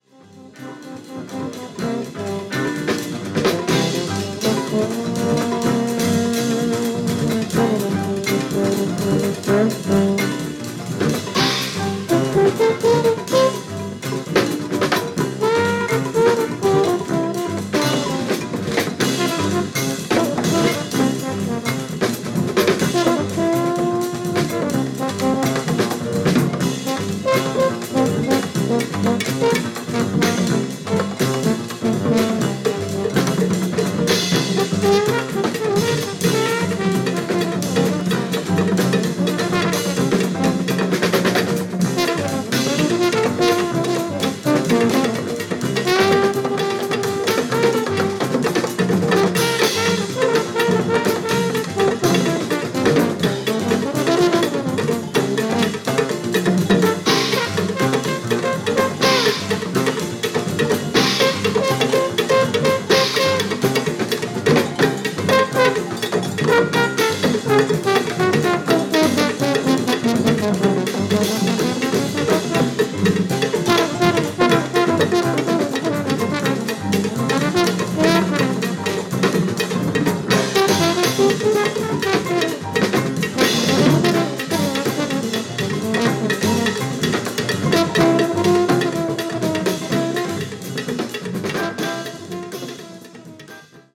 Saxophone, Flute